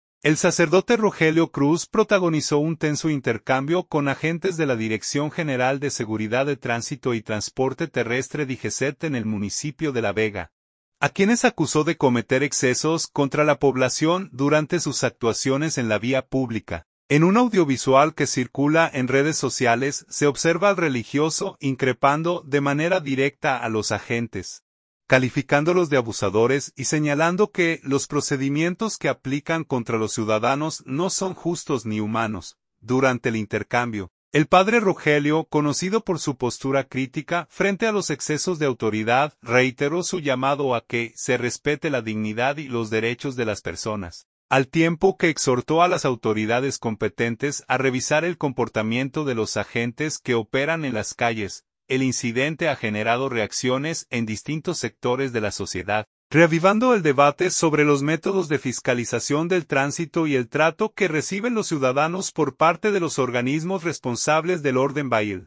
En un audiovisual que circula en redes sociales, se observa al religioso increpando de manera directa a los agentes, calificándolos de “abusadores” y señalando que los procedimientos que aplican contra los ciudadanos no son justos ni humanos.